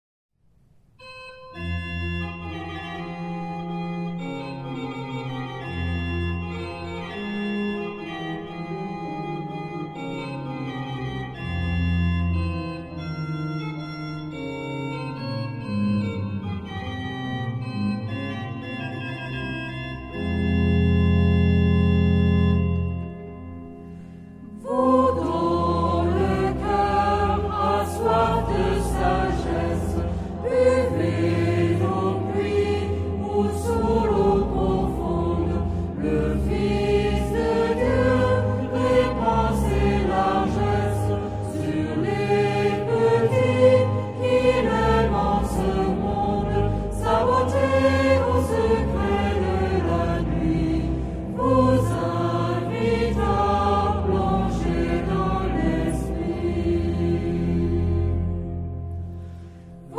Genre-Style-Forme : Cantique ; Sacré
Caractère de la pièce : recueilli
Type de choeur : SATB OU unisson  (4 voix mixtes )
Instruments : Orgue (1)
Tonalité : do mineur